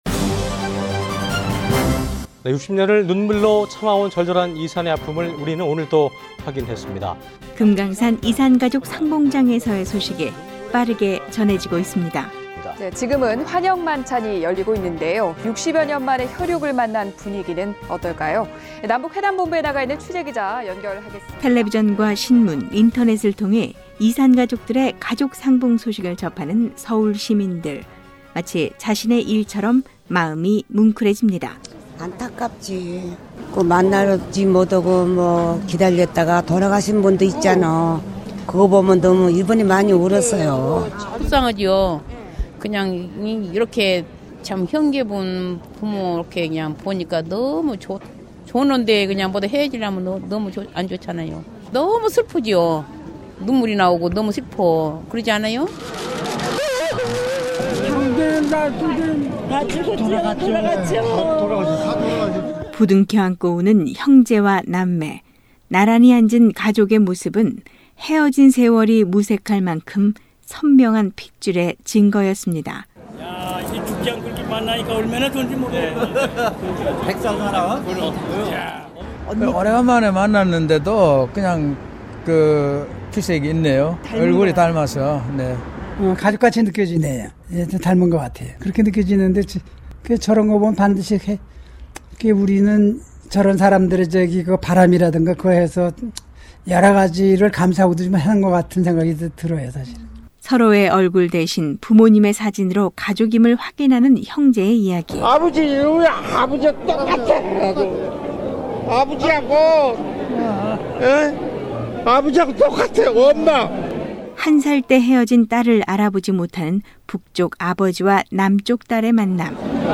오늘은 금강산에서 진행되고 있는 이산가족 상봉을 바라보는 서울시민들과 탈북자들의 목소리를 들어보겠습니다. TV와 신문 등 언론을 통해 소식을 접하고 있는 서울시민들은 이산가족들의 사연에 귀를 기울였고, 더 많은 가족들이 건강한 모습으로 만날 수 있도록 하루 빨리 이산가족들의 만남이 정례화되어야 한다고 입을 모았습니다.